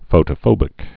(fōtə-fōbĭk)